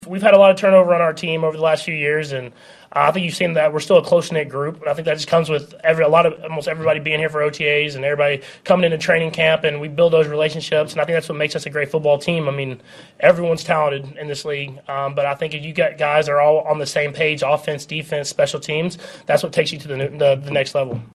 Quarterback Patrick Mahomes says the OTAs are more about building relationships.
5-25-patrick-mahomes-about-building-relationships.mp3